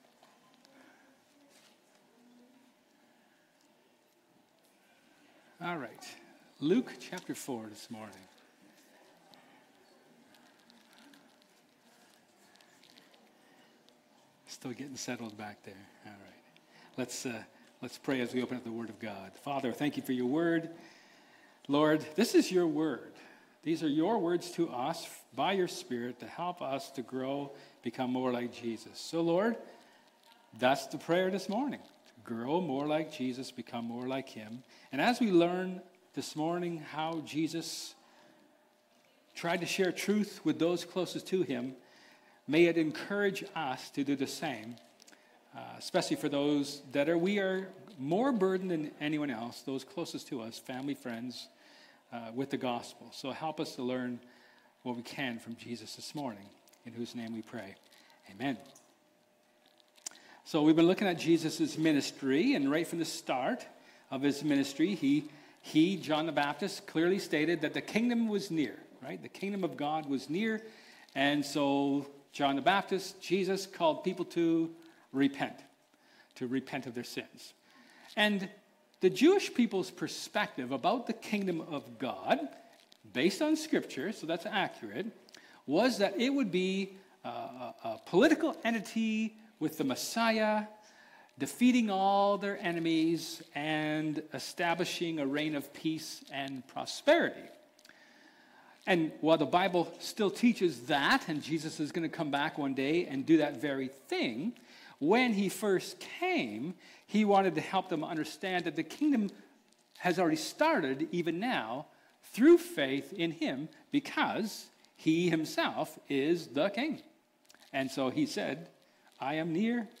Luke 1:1-4 Service Type: Sermon